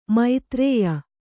♪ maitrēya